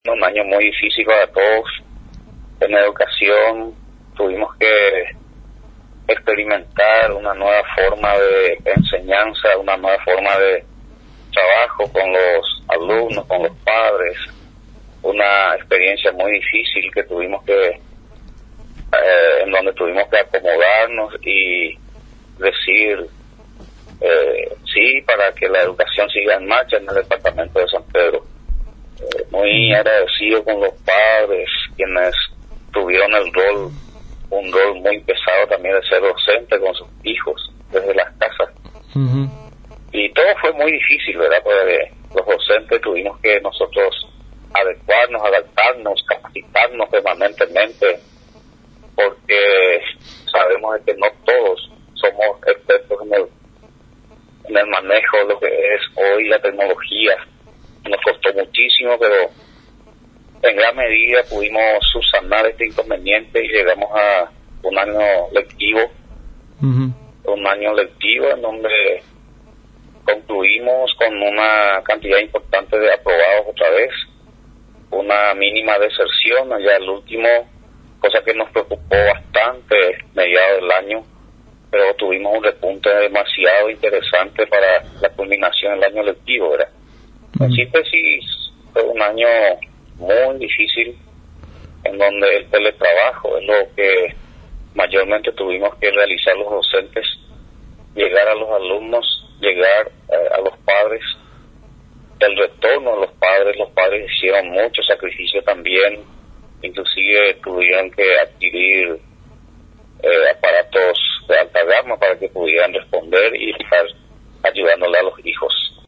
El Lic. Alcides Barreto, director departamental de Educación de San Pedro, en comunicación con nuestra emisora, realizó una breve evaluación de lo que ha sido la culminación de este año difícil para el sistema educativo, teniendo en cuenta que todas las actividades educativas se realizaron en medio de esta pandemia del Covid-19.